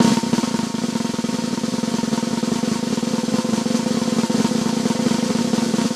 drumroll.ogg